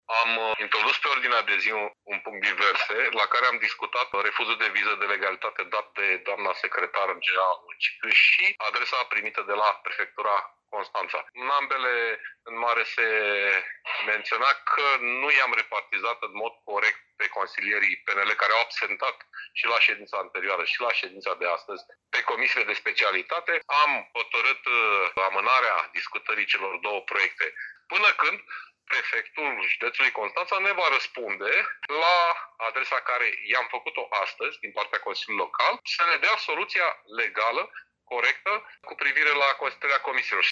La întrunirea convocată de aleșii locali de la PSD, AUR și USR au lipsit consilierii liberali. Unul dintre inițiatorii celor două proiecte, social democratul Paul Foleanu, a explicat ce s-a întâmplat.